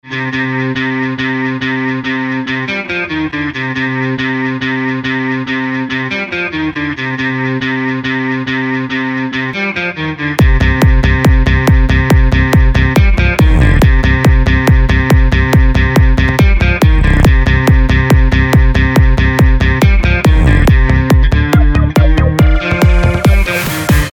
• Качество: 320, Stereo
гитара
без слов
club
house
МОщная хаус музыка без слов